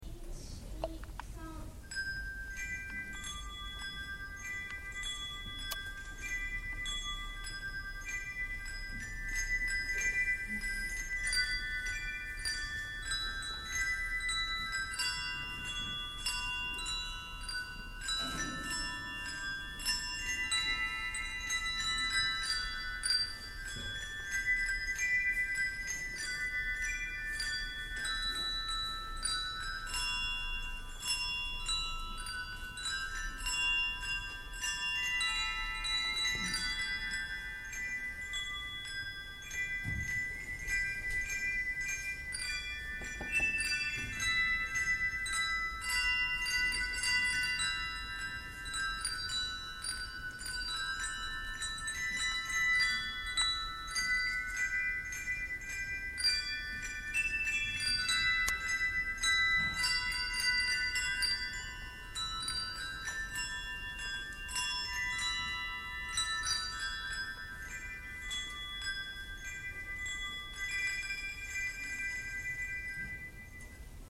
文化祭舞台発表 音楽部（ハンドベル）